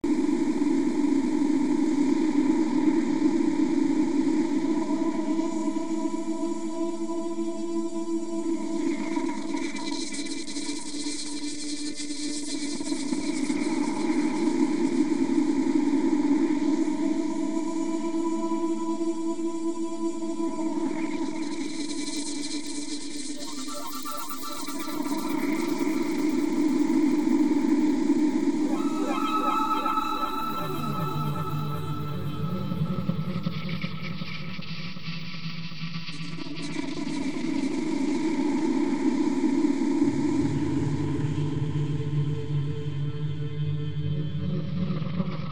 Soft Synth